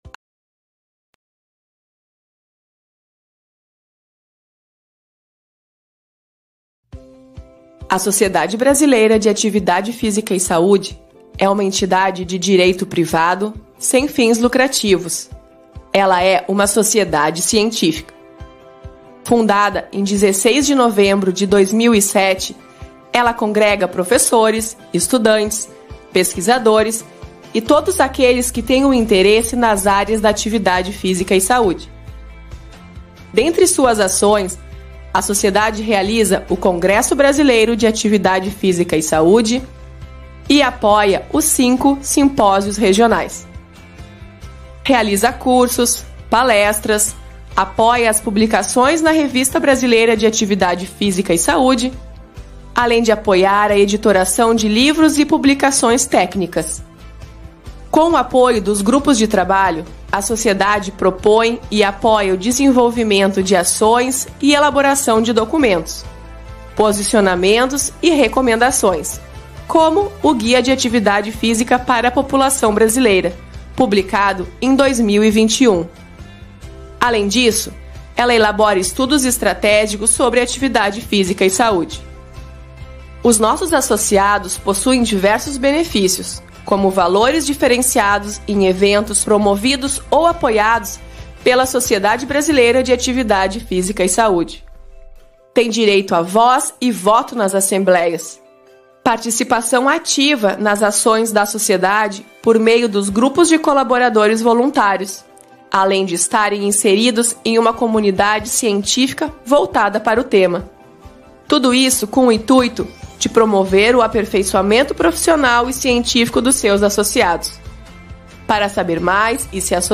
Prepare-se para uma conversa que vai muito além dos números, explorando soluções e oportunidades para o bem-estar de todos.